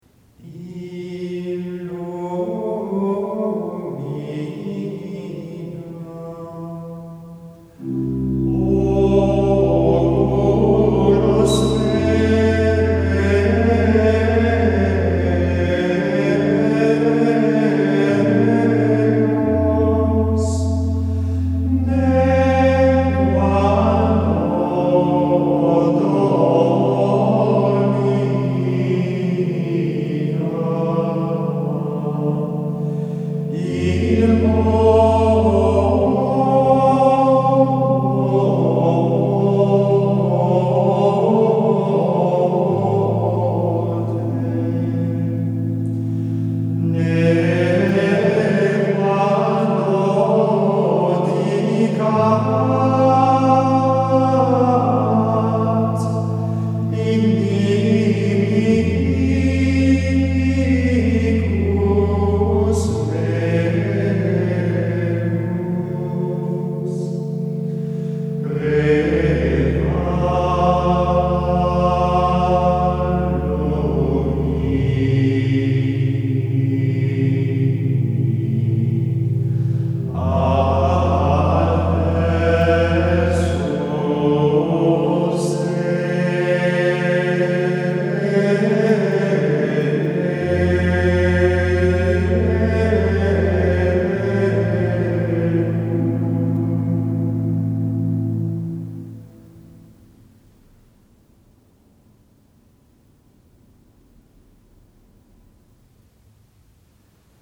Roman Gradual – Extraordinary Form of the Roman Rite
04-offertoire-2.mp3